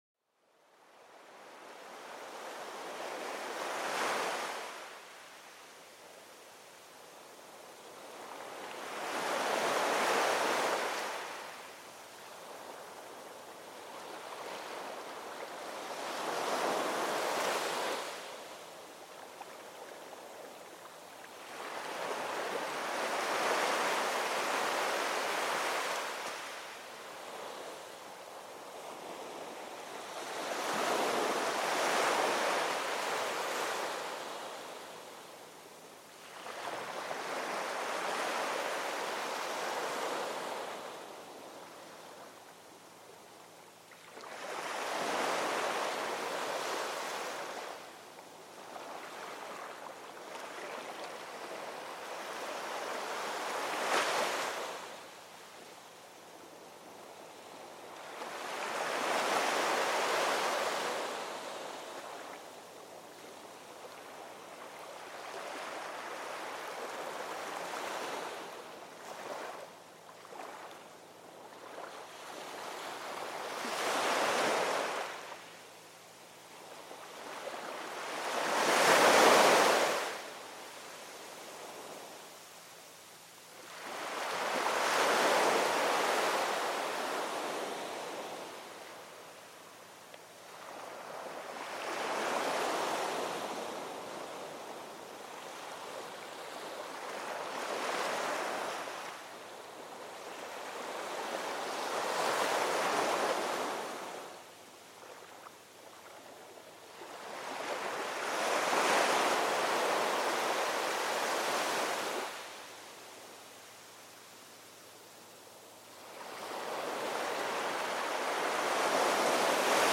SONIDOS DE LA NATURALEZA PARA LA RELAJACIÓN